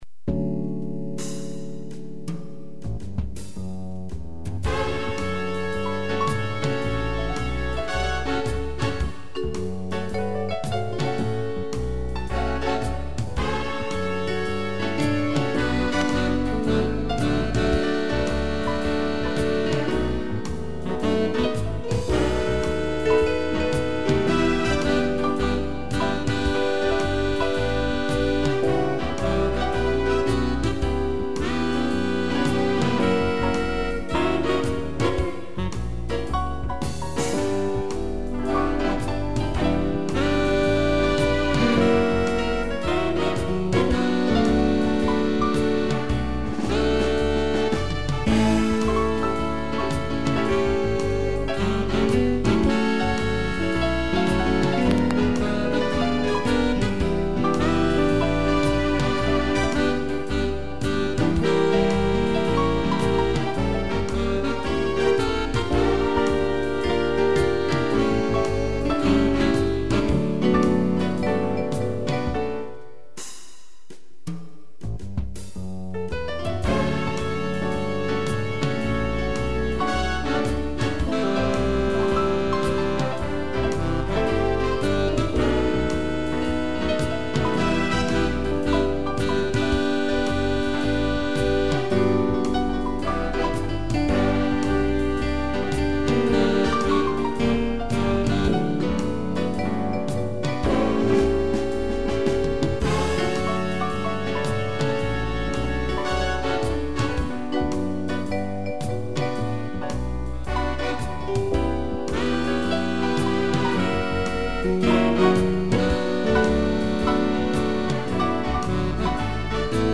piano
(instrumental)